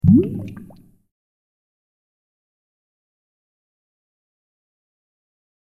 دانلود آهنگ آب 46 از افکت صوتی طبیعت و محیط
دانلود صدای آب 46 از ساعد نیوز با لینک مستقیم و کیفیت بالا
جلوه های صوتی